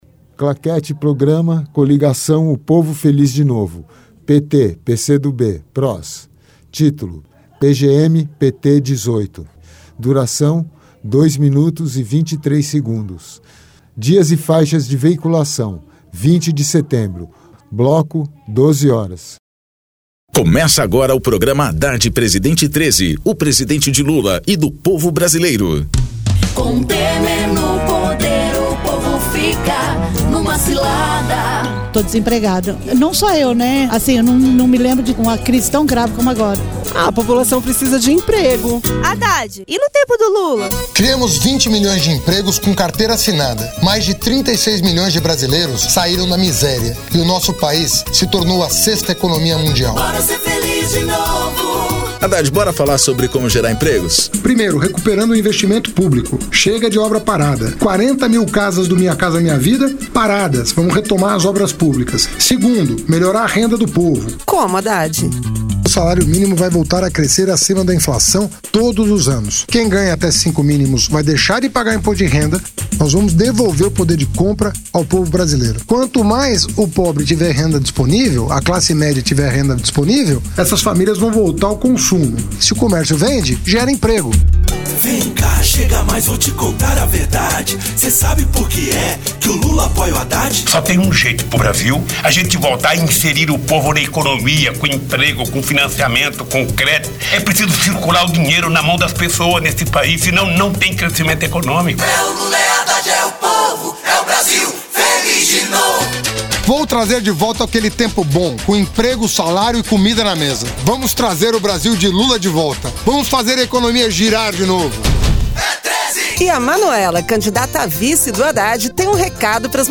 Gênero documentaldocumento sonoro
Descrição Programa de rádio da campanha de 2018 (edição 18) - 1° turno